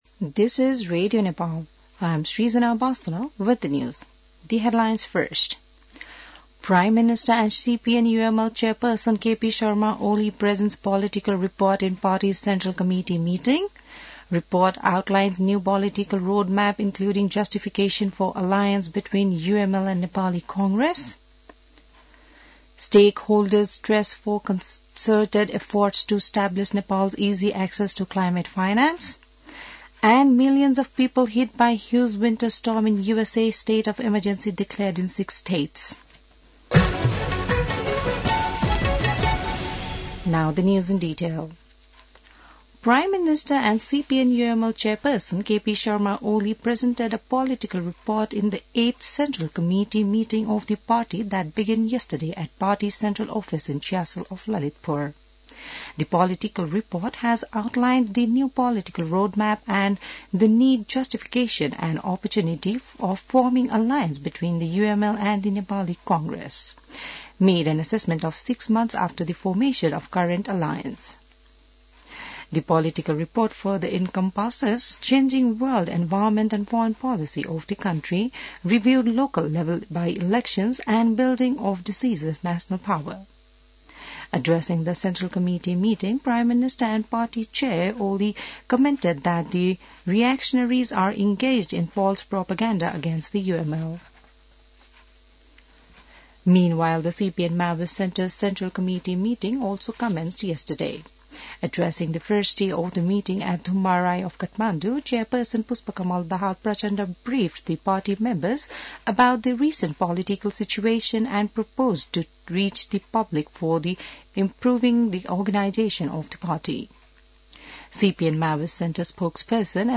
बिहान ८ बजेको अङ्ग्रेजी समाचार : २३ पुष , २०८१